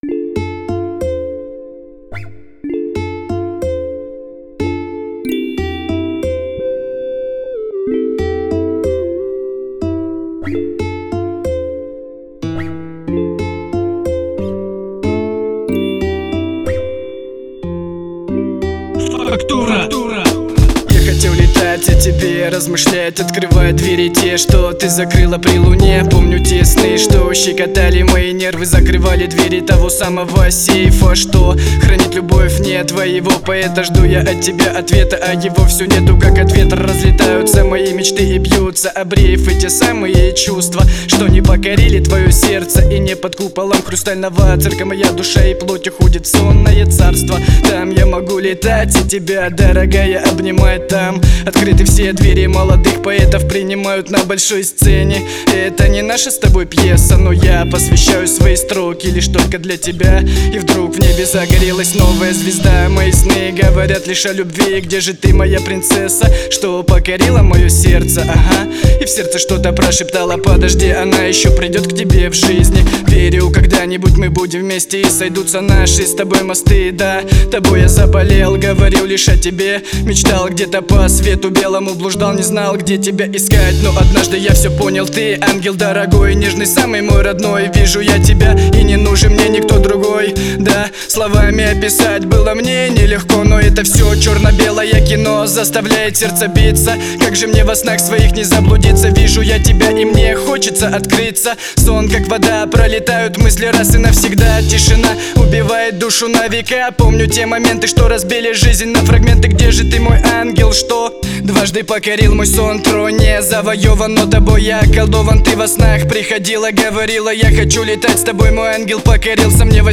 Рэп
Под биты